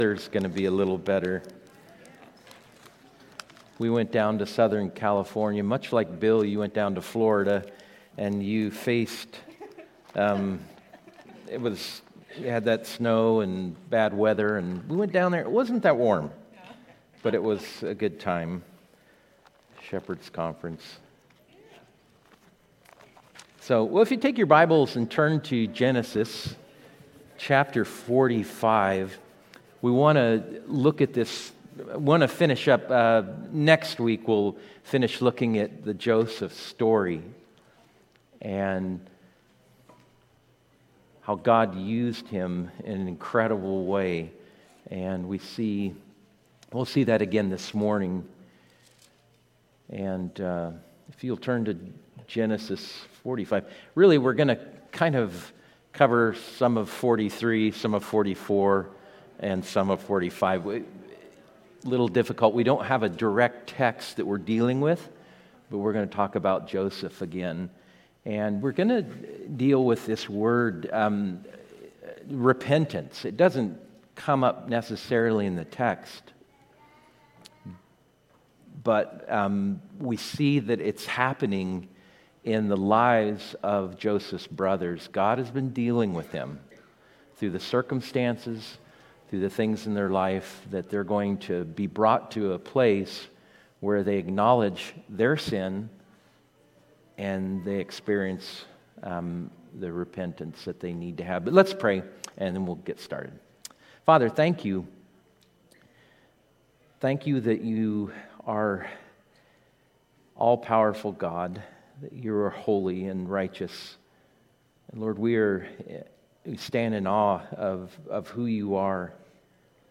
Adult Sunday School 3/9/25